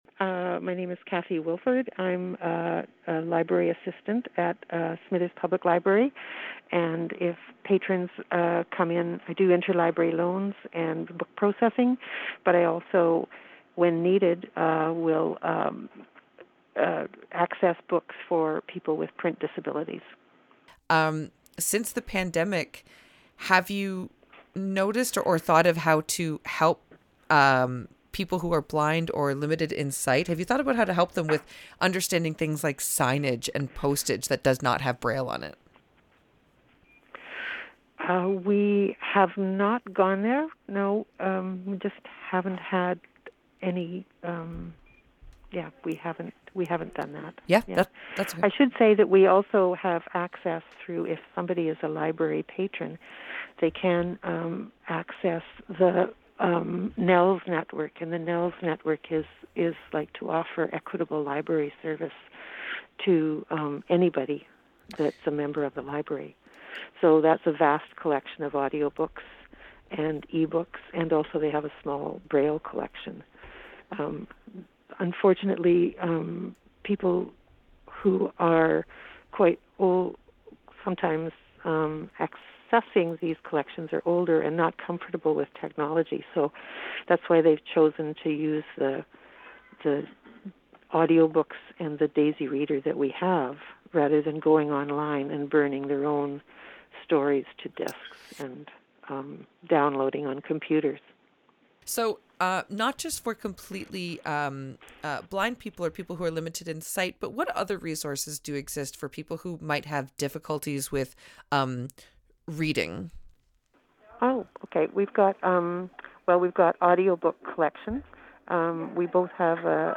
Resources-for-the-Blind-in-Northern-BC_CICK-News.mp3